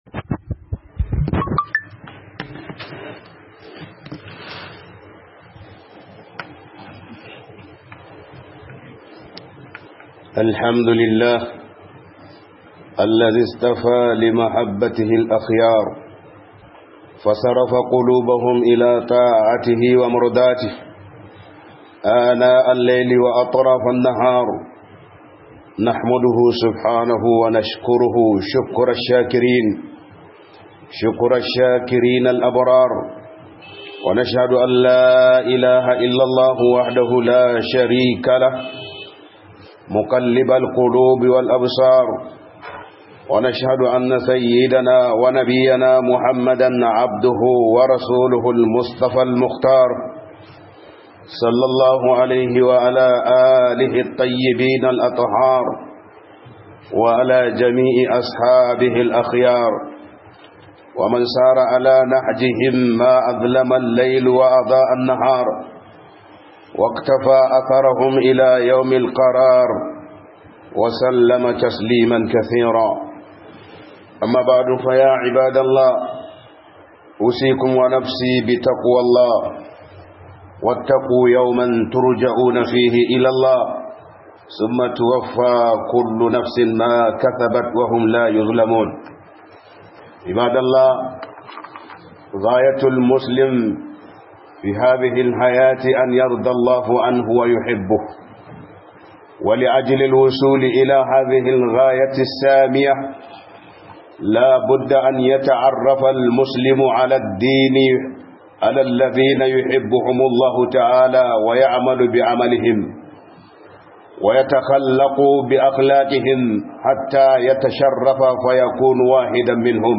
HUDUBA